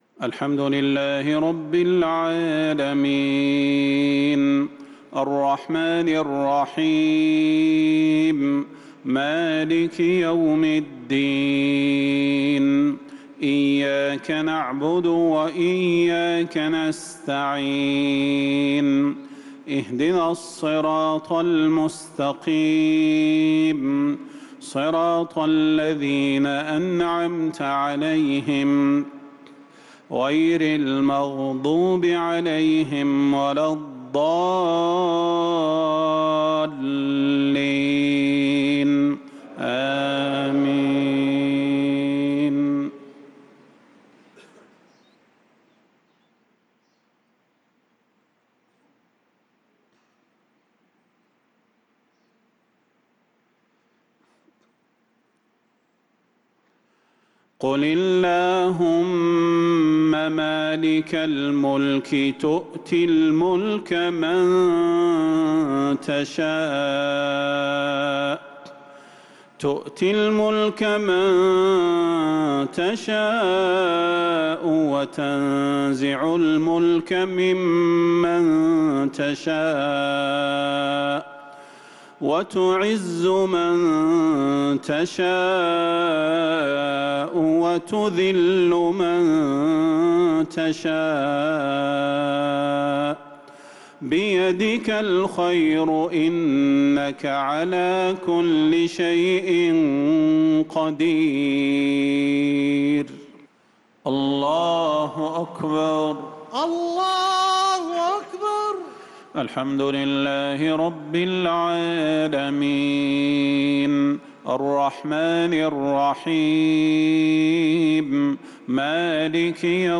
مغرب الأحد 5-3-1446هـ من سورة آل عمران | Maghrib prayer from surat al-eimran | 8-9-2024 > 1446 🕌 > الفروض - تلاوات الحرمين